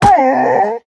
Minecraft Version Minecraft Version latest Latest Release | Latest Snapshot latest / assets / minecraft / sounds / mob / wolf / cute / hurt1.ogg Compare With Compare With Latest Release | Latest Snapshot
hurt1.ogg